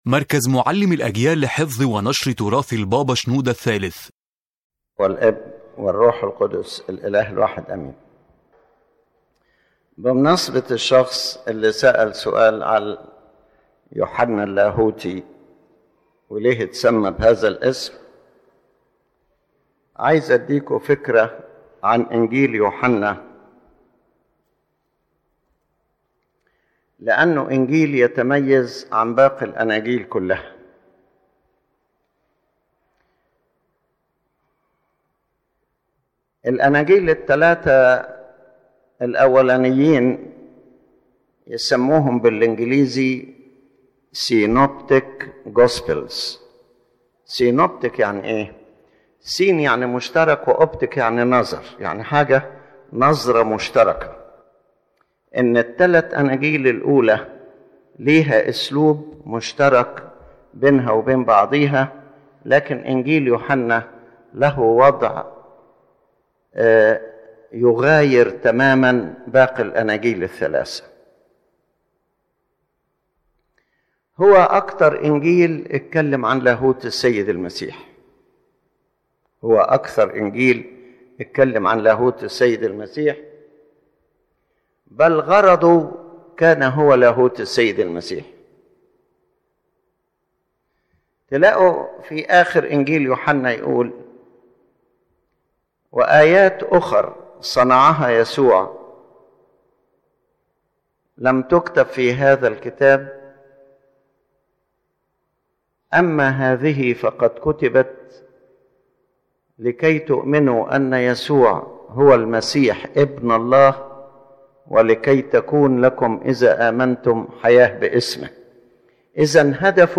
This lecture explains the uniqueness of the Gospel of John compared with the other three Gospels. It clearly focuses on the divinity of the Lord Jesus Christ and presents a deep revelation of His divine person and His relationship with the Father and with humanity.